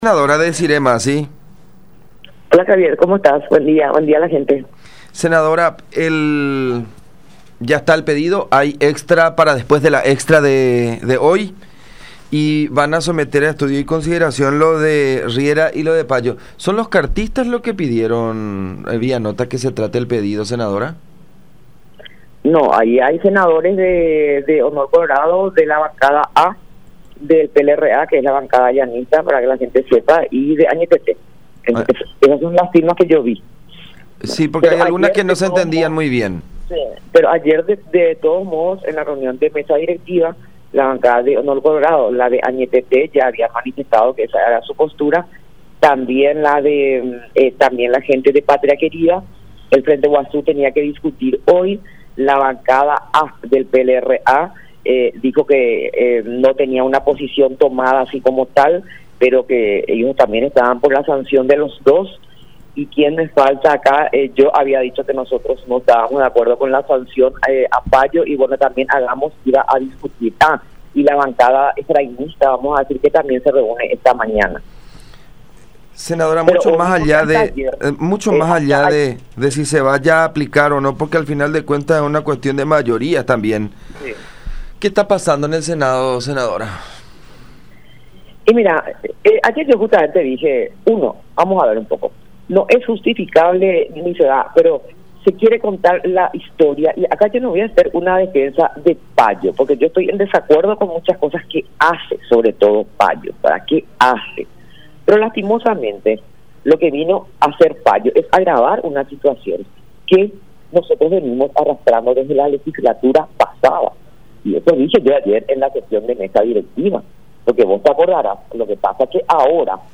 Al respecto, se refirió la senadora Desirée Masi, del PDP, cuestionó la actitud de Paraguayo Cubas, ya que citó problemas y cuestiones personales con Enrique Riera en plena sesión, señalando que el Congreso no es el lugar para tratar esos temas.